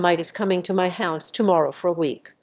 translation4_ShuttingDoor_1.wav